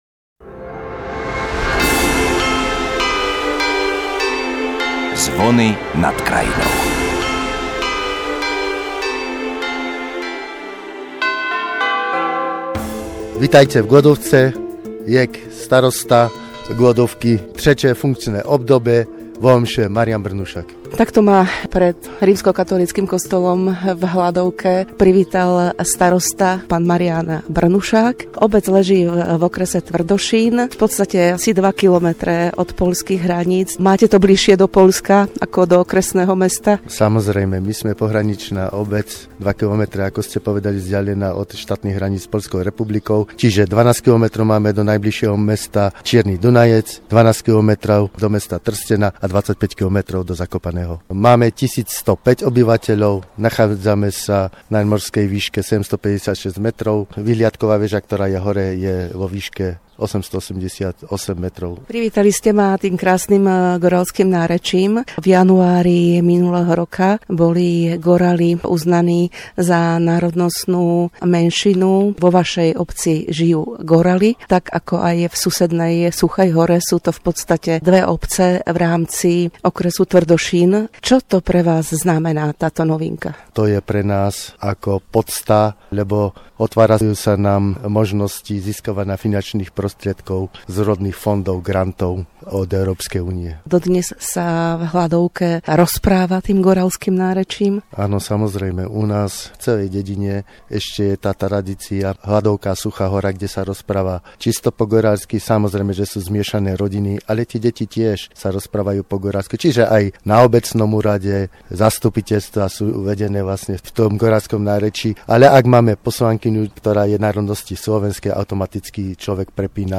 Navštívila nielen obecný úrad, materskú školu, základnú školu, rozhľadňu, no stihla sa o Hladovke porozprávať aj s tými najmladšími aj najstaršími.